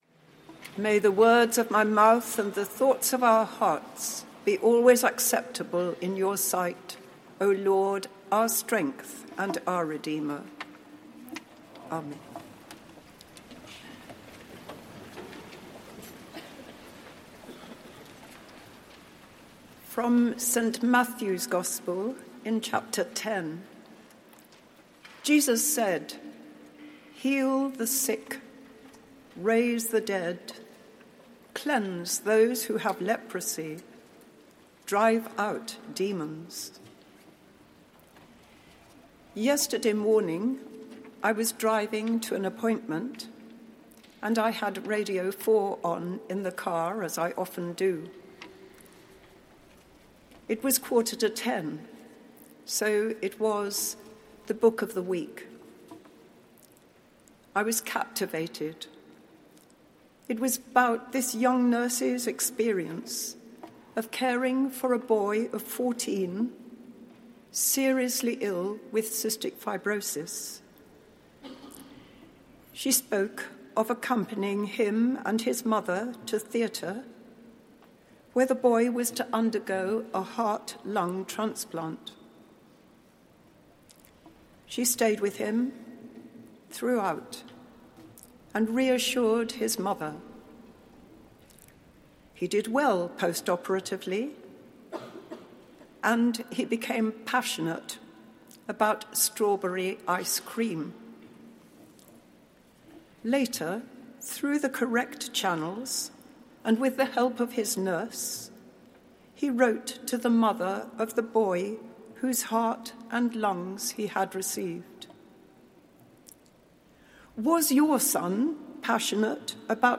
Address given at a Service to Commemorate the Life of Florence Nightingale